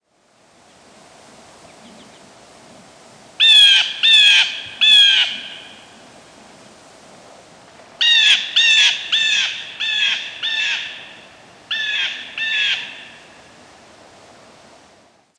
Blue Jay Cyanocitta cristata
Flight call description A loud "jay" or series of "jay" calls may sometimes be used as a flight call (see Behavior).
Diurnal calling sequences:
Bird in short flight, then perched. Background calls include Yellow-rumped Warbler, White-throated Sparrow, Fox Sparrow, Northern Cardinal, and American Goldfinch.